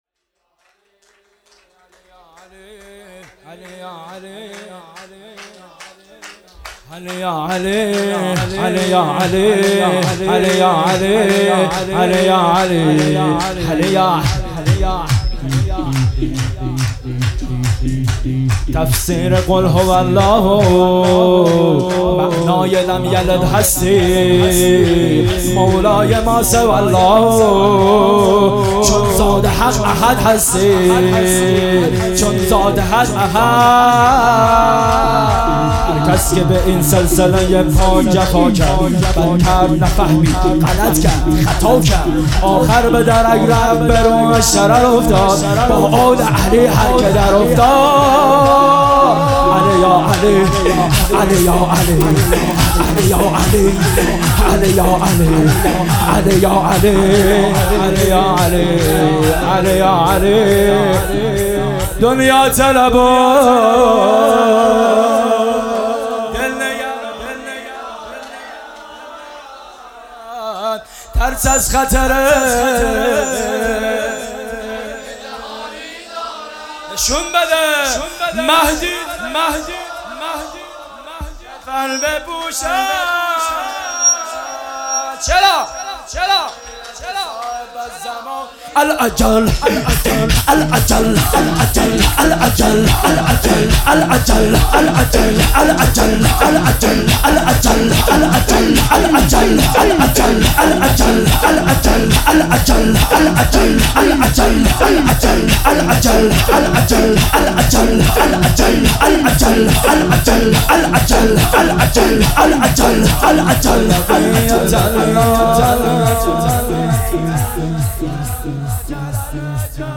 جشن ولادت مولی امیرالمومنین علی علیه السلام